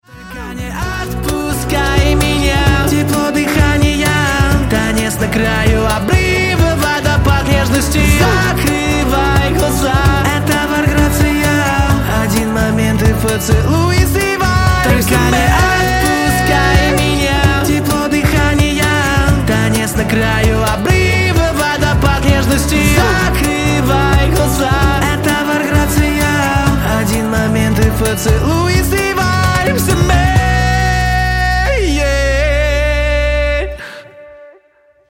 Рингтон